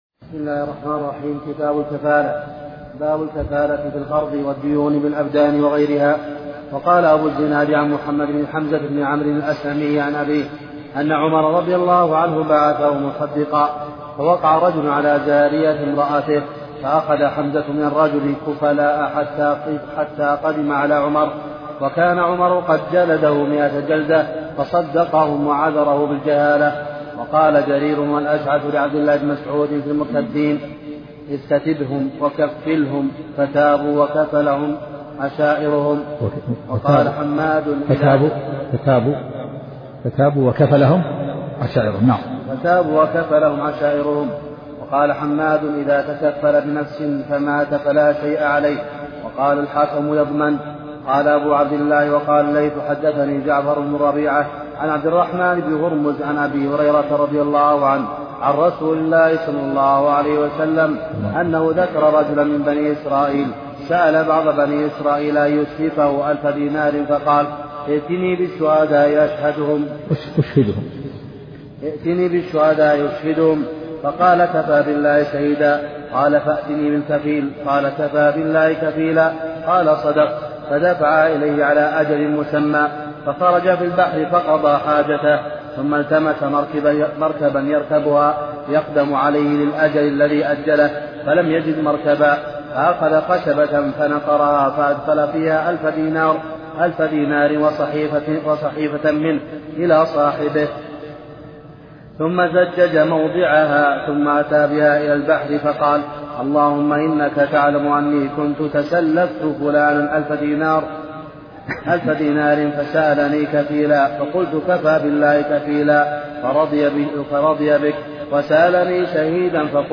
محاضرة صوتية نافعة